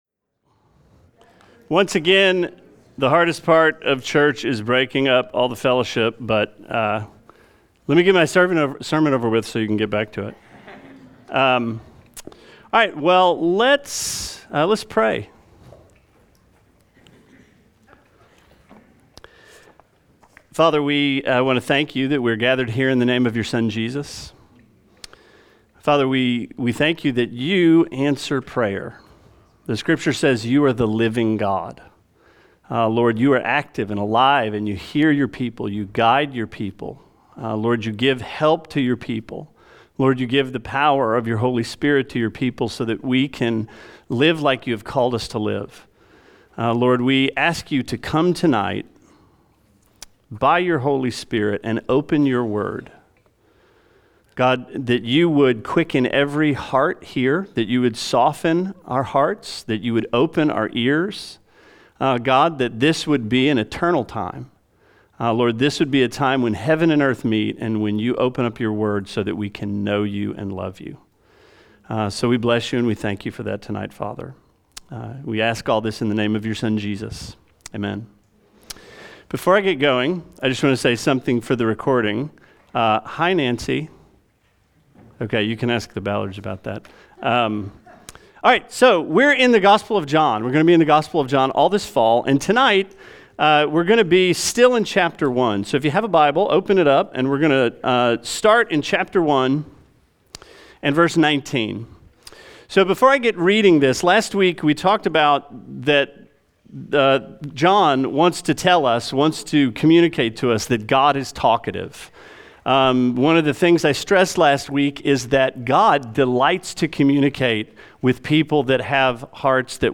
Sermon 09/07: John – Come and See